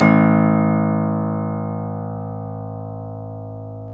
Index of /90_sSampleCDs/Club-50 - Foundations Roland/PNO_xTack Piano/PNO_xTack Pno 1D